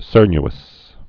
(sûrny-əs)